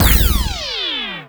powerRegularShoot.wav